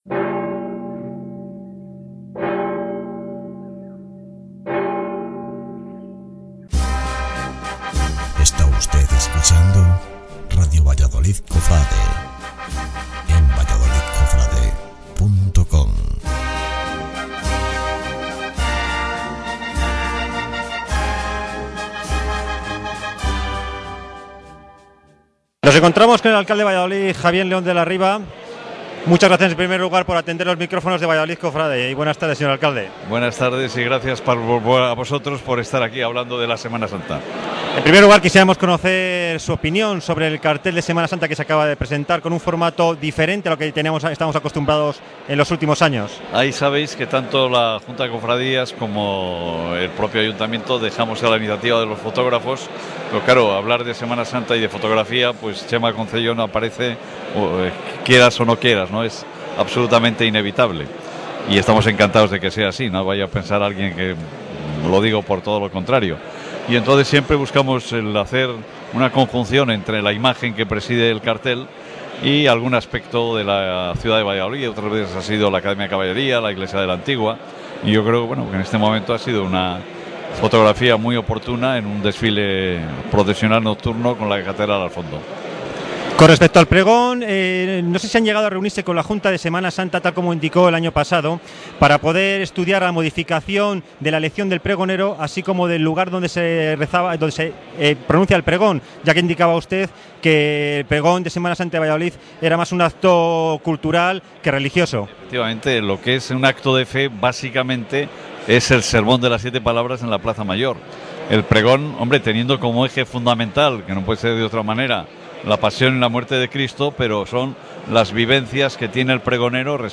ENTREVISTA AL ALCALDE DE VALLADOLID
Entrevista al alcalde en la Presentación del Cartel y Pregonero de la Semana Santa de Valladolid 2013
EntrevistaAlcalde.mp3